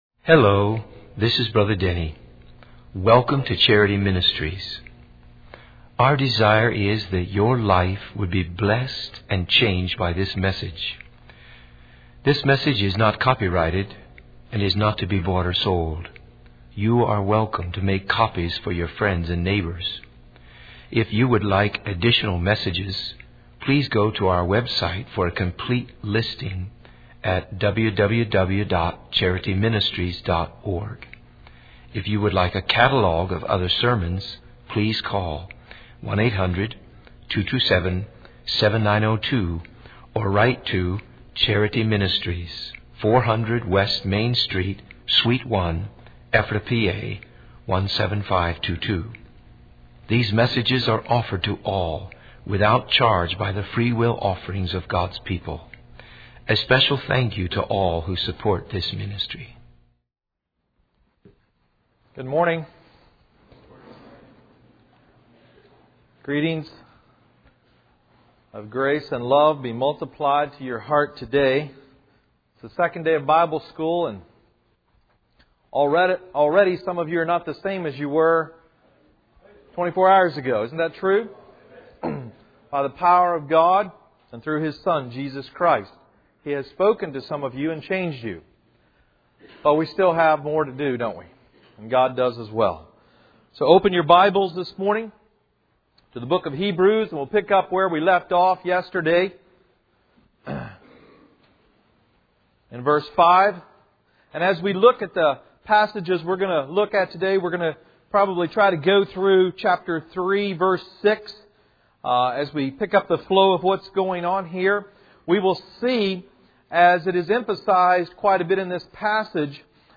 In this sermon, the preacher emphasizes the difference between those who are born again and those who are not. He highlights the importance of having a relationship with Jesus Christ and being indwelt by the Spirit of God.